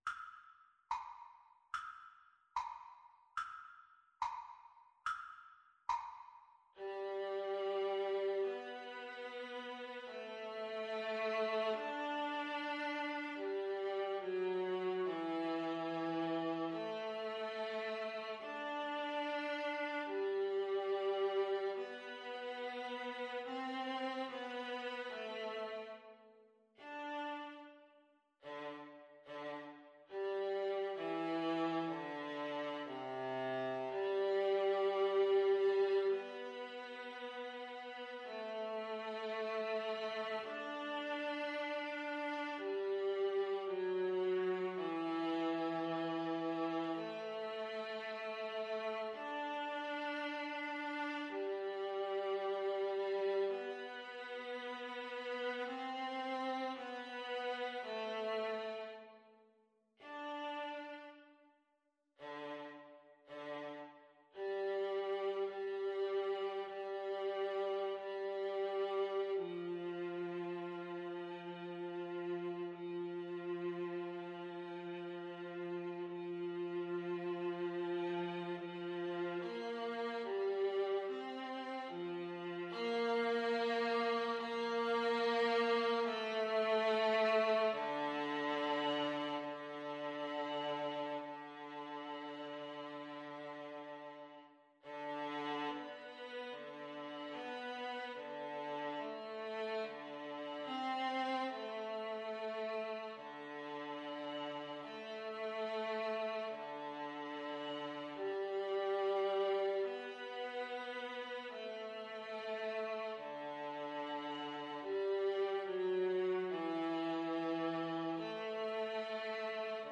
Play (or use space bar on your keyboard) Pause Music Playalong - Player 1 Accompaniment reset tempo print settings full screen
G major (Sounding Pitch) (View more G major Music for Viola Duet )
Andantino =72 (View more music marked Andantino)
Viola Duet  (View more Intermediate Viola Duet Music)